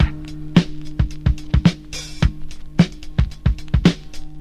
• 109 Bpm Fresh Drum Loop Sample G# Key.wav
Free breakbeat - kick tuned to the G# note. Loudest frequency: 503Hz
109-bpm-fresh-drum-loop-sample-g-sharp-key-VNl.wav